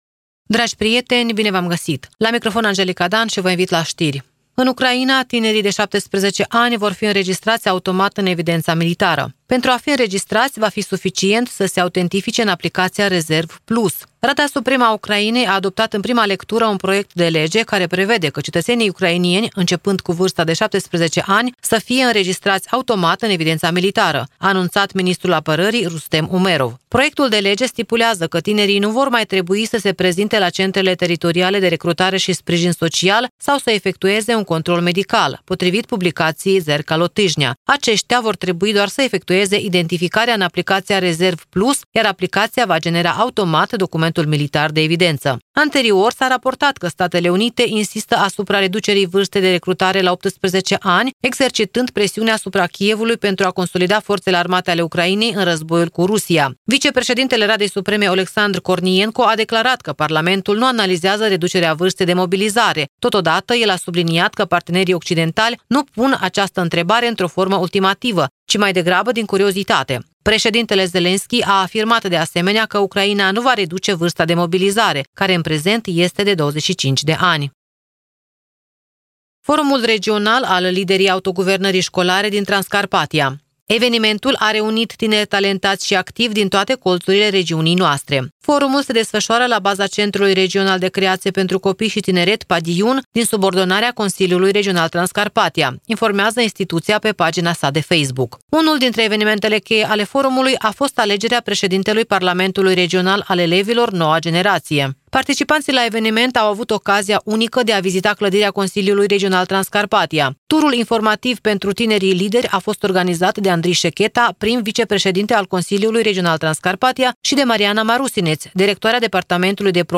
Știri de la Radio Ujgorod.